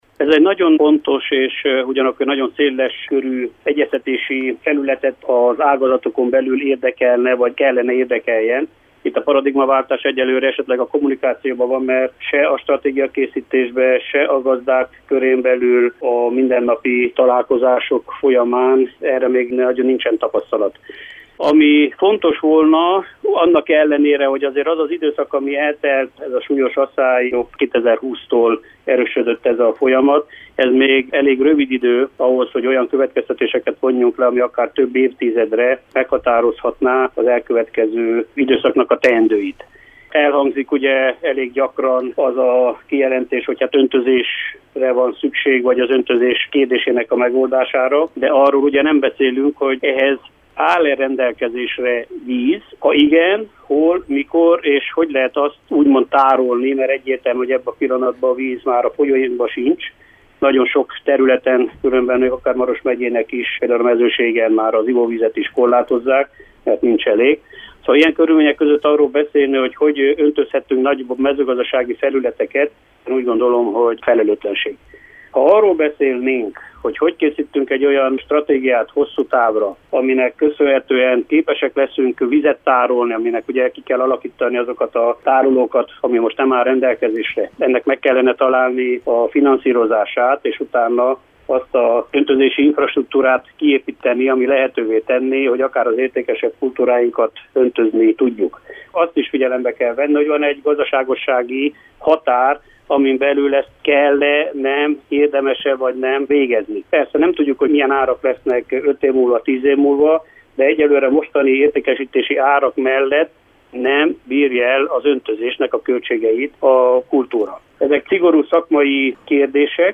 Rádiónknak adott interjújában azt is hangsúlyozta, hogy megfelelő stratégiával fentarthatóan és gazdaságosan is megoldható lenne az öntözés.